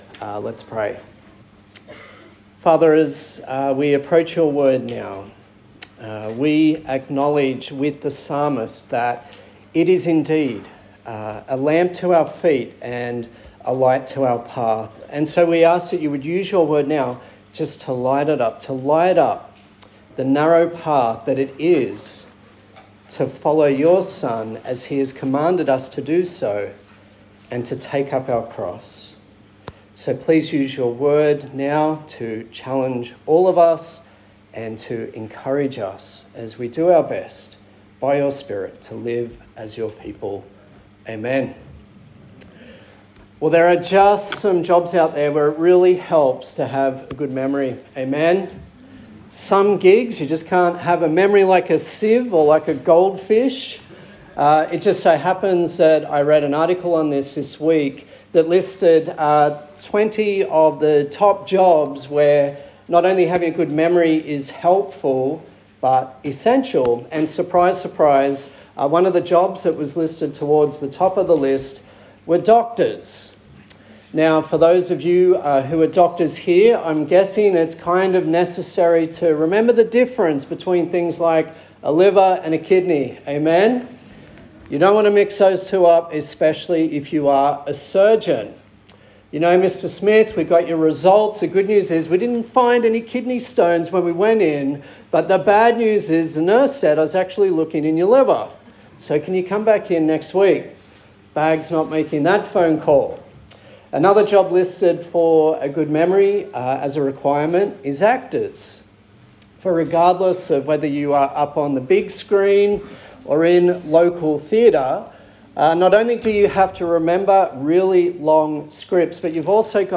Leviticus Passage: Leviticus 2 and 3 Service Type: Sunday Service